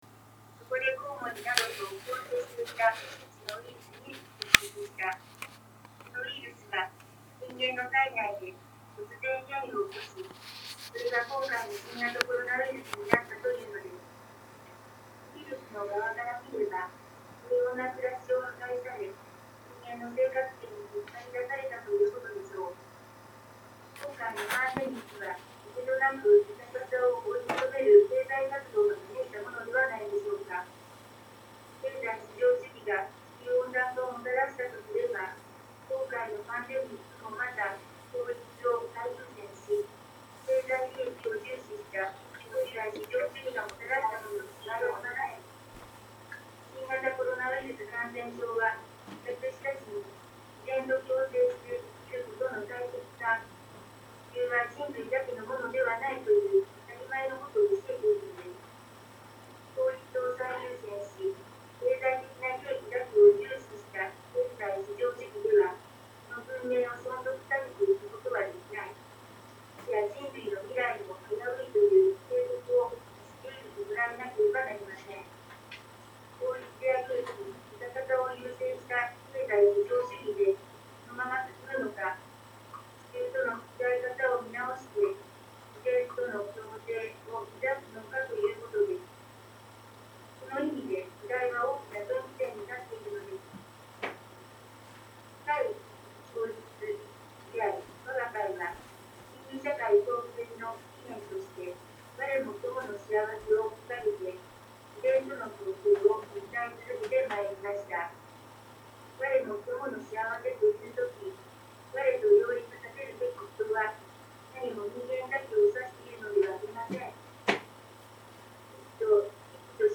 元朝式は1月1日の午前5時から式場で開かれる。